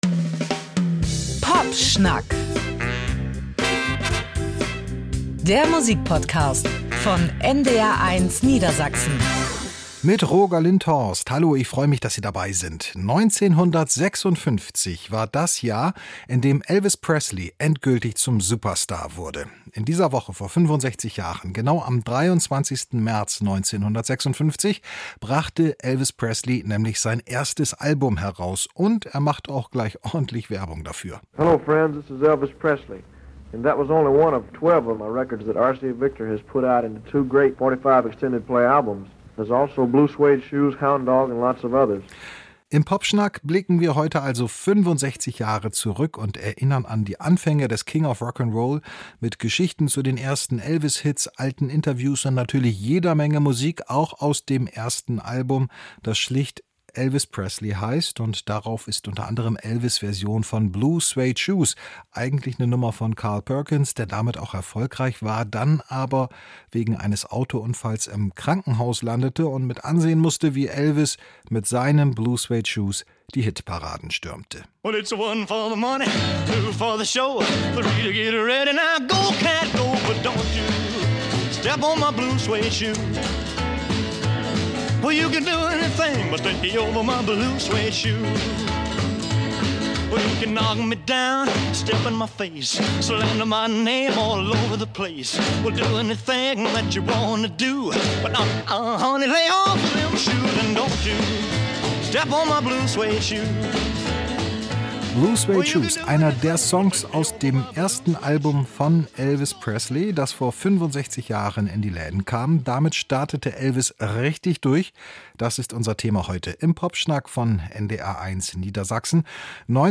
Prominente Geburtstagskinder, legendäre Alben, Hit-Geschichten und Aktuelles aus der Popmusik stehen im Mittelpunkt des Talks der Musikredakteure